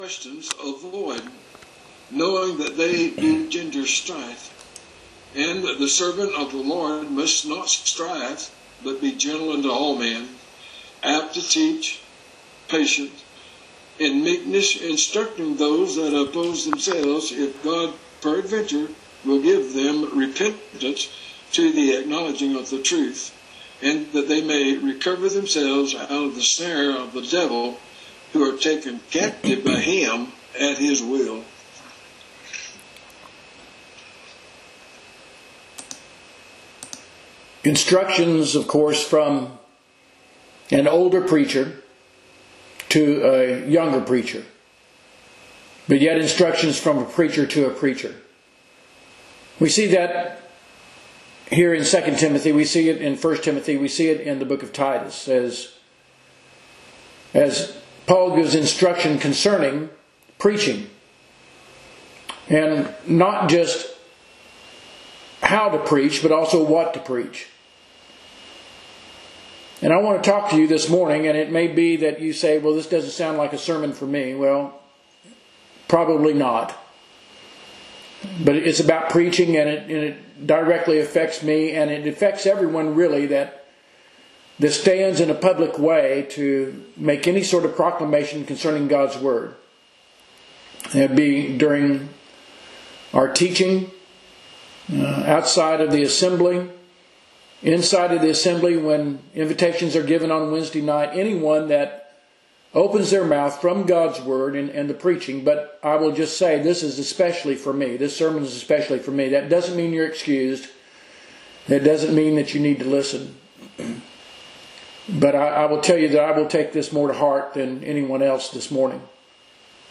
Preaching